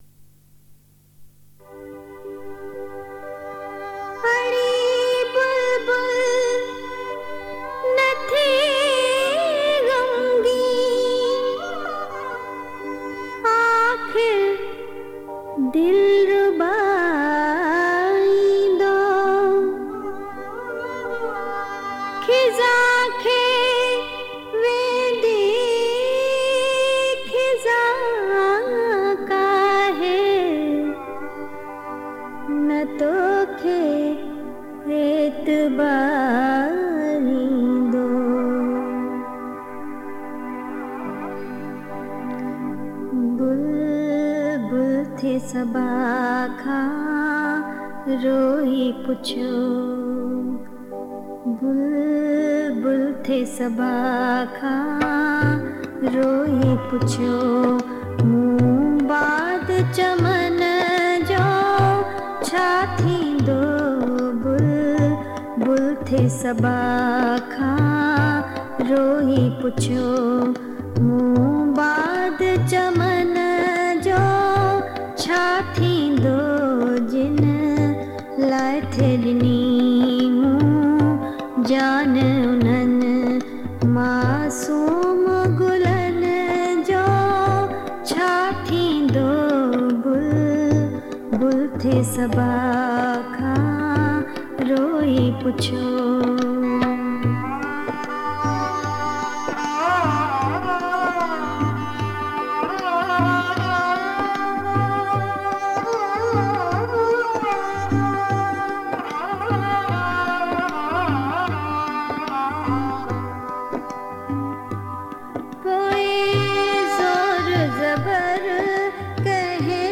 Sindhi Geet and Kalam